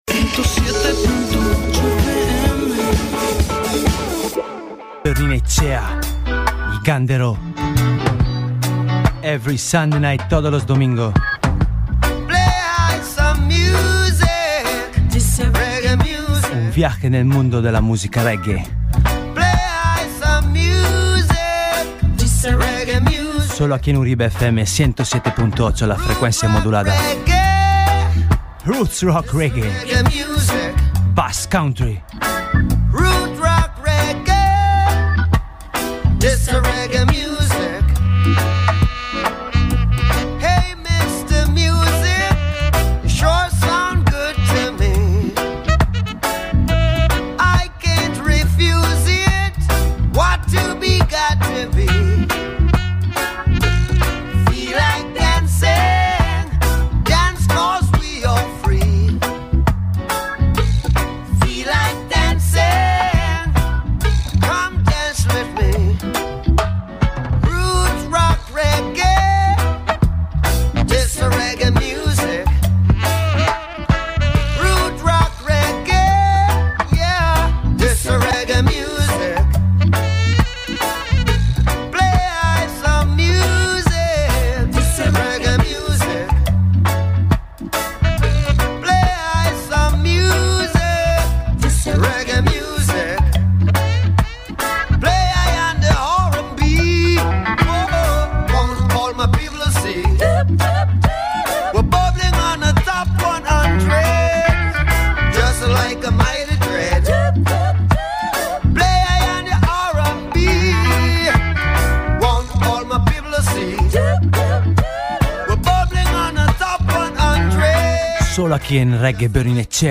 rub a dub showcase